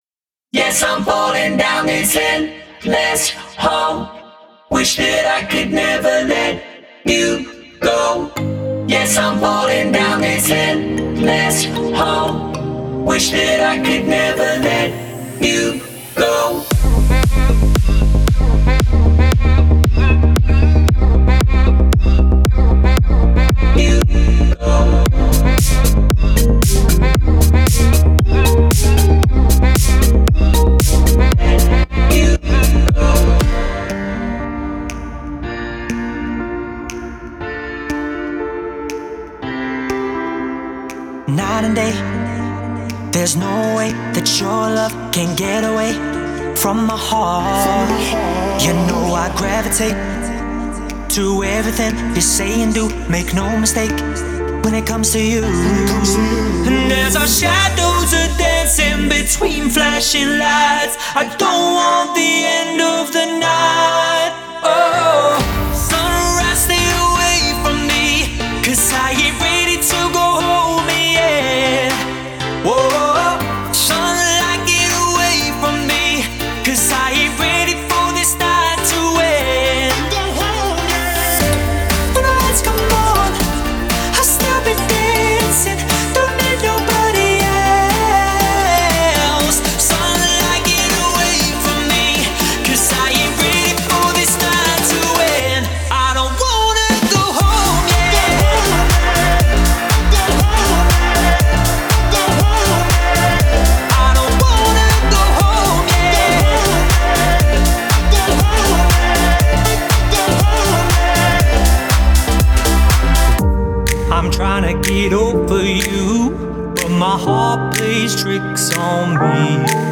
强大而悦目的音乐节人声可以提升您的音轨，并为观众带来激动人心的颠簸，其有趣的质感和声音设计可将您的音轨带到主舞台。